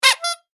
パフッ2.mp3